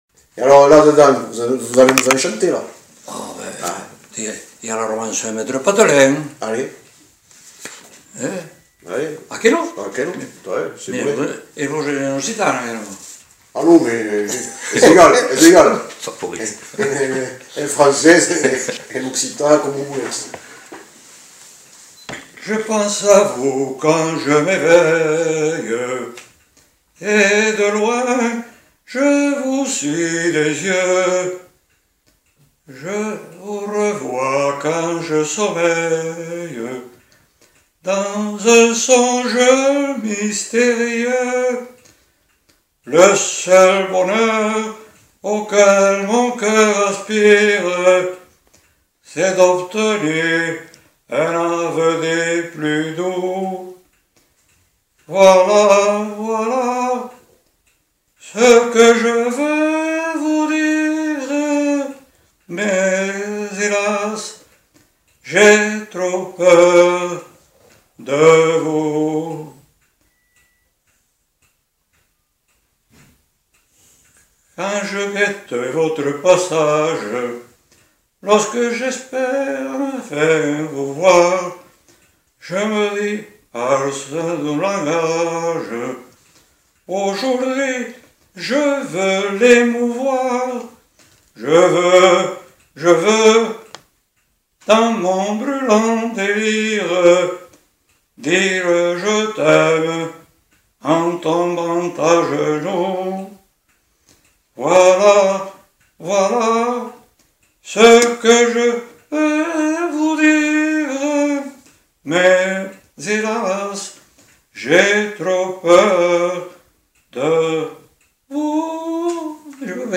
Aire culturelle : Lauragais
Lieu : Le Faget
Genre : chant
Effectif : 1
Type de voix : voix d'homme
Production du son : chanté
Notes consultables : Chant suivi d'une question sur les complaintes qui n'évoquent rien à l'informateur.